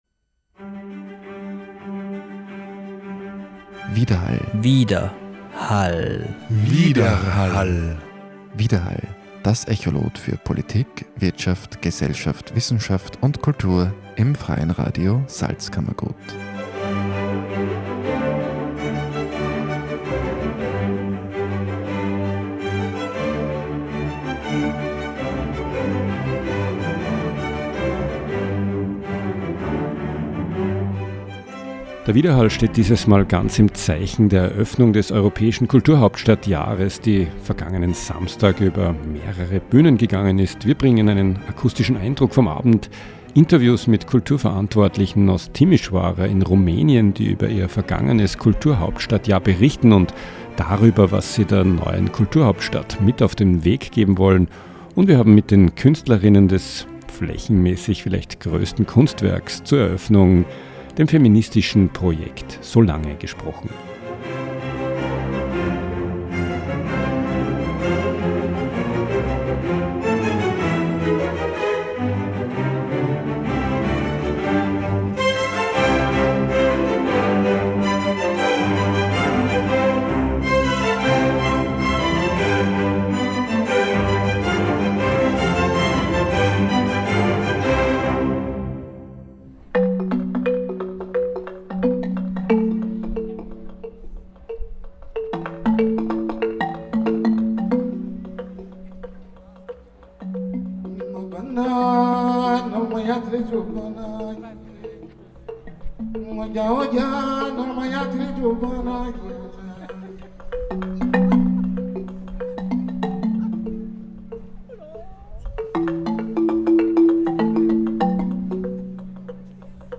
Eröffnung Kulturhauptstadt Bad Ischl Salzkammergut